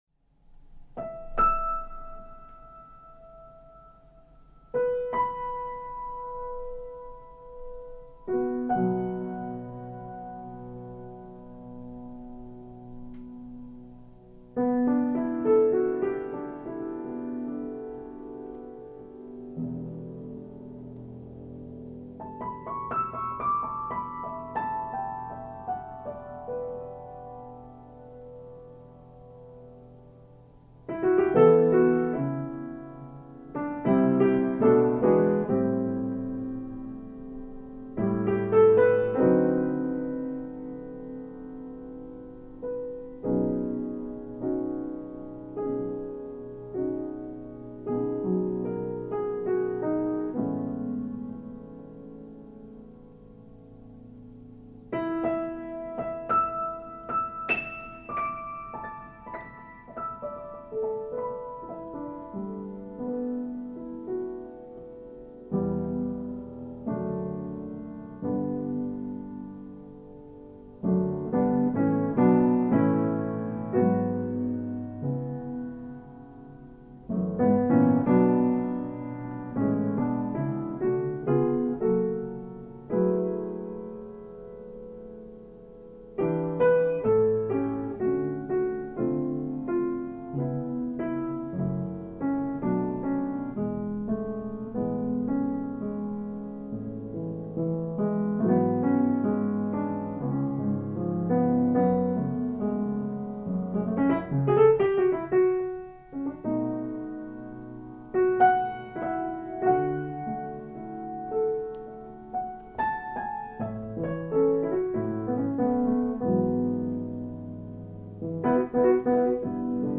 Live Recording
improvising on His piano after I tuned it
Enjoy this improvised, relaxing music as it flows around you. Better with headphones but also sounds lovely on speakers.